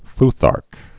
(fthärk)